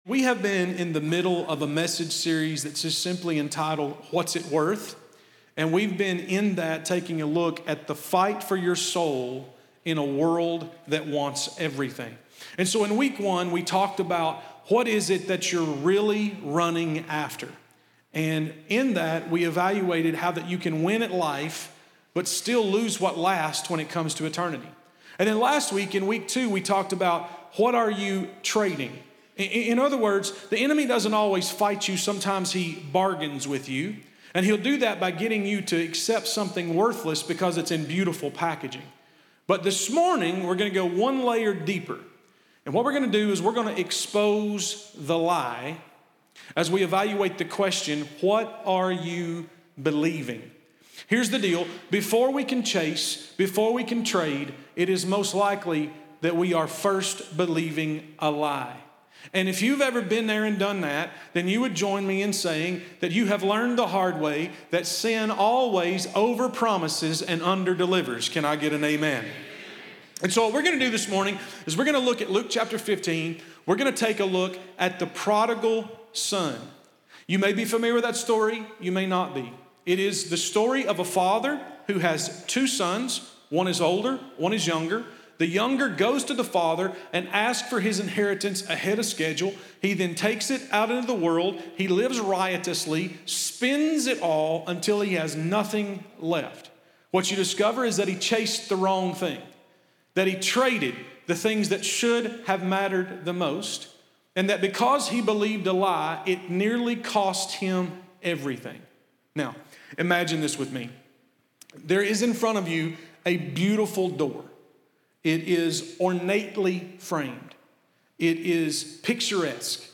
In this powerful message, Pastor dives deep into Luke 15 and the story of the prodigal son — revealing how believing the enemy’s lies can lead us far from home, but never beyond the reach of God’s grace. From the illusion of freedom to the beauty of the Father’s running embrace, this sermon exposes the traps of deception and calls every soul to come home.